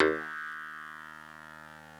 genesis_bass_027.wav